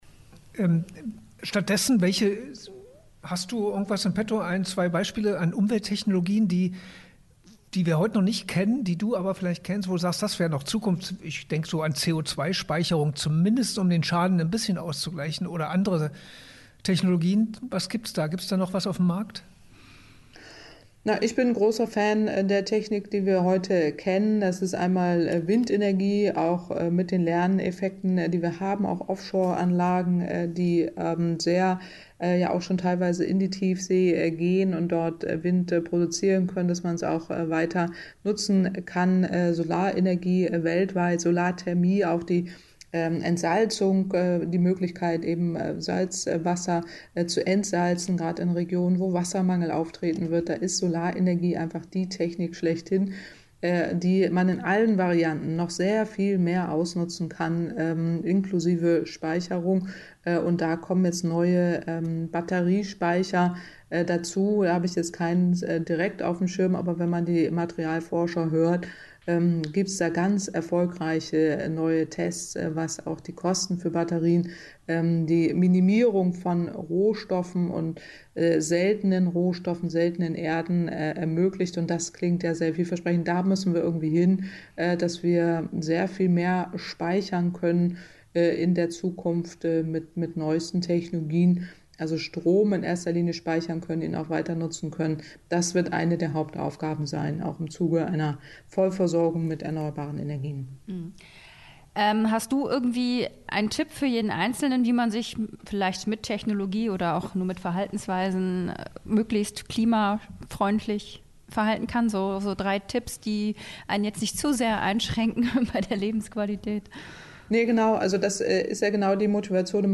Hier finden Sie eine Reihe von Mitschnitten aus Interviews, Lesungen und Vorträgen.